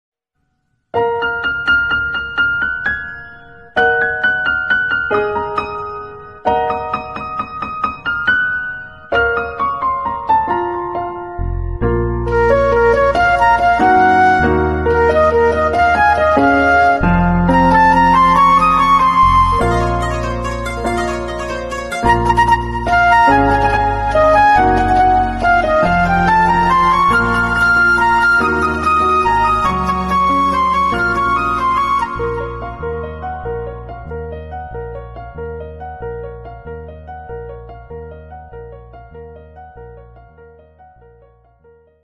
Categories Malayalam Ringtones